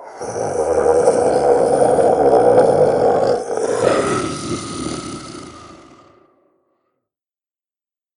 beast_roar_long2.ogg